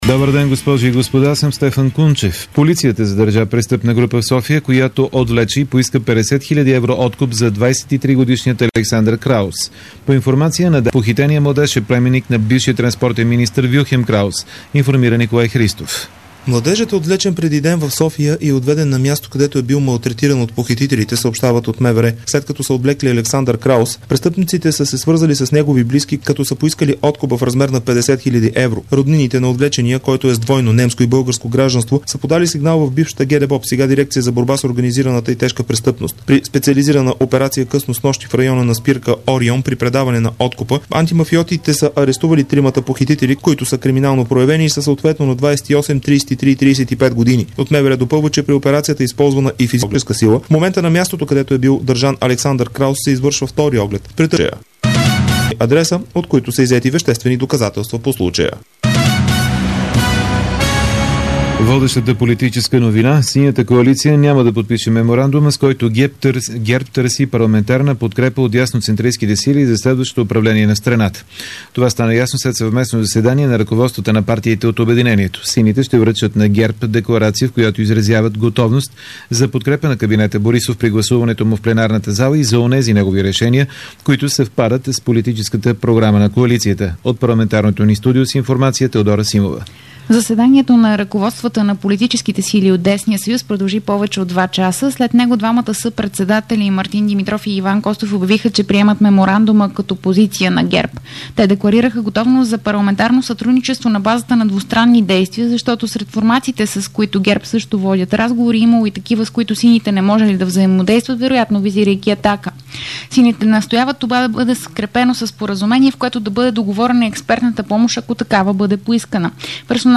Обeдна информационна емисия - 17.07.2009